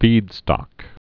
(fēdstŏk)